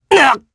Ezekiel-Vox_Damage_jp_03.wav